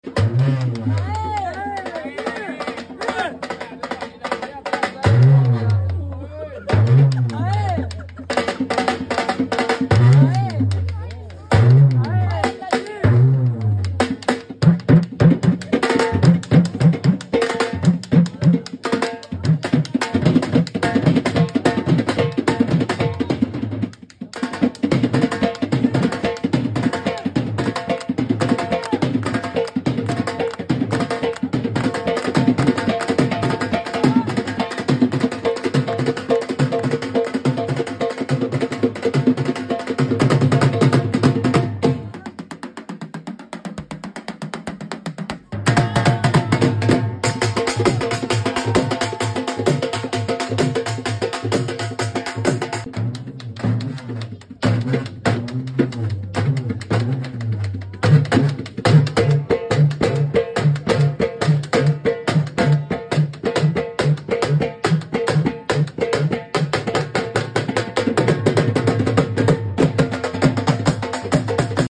beautiful Dhol beat prfome